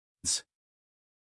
us_phonetics_sound_kids.mp3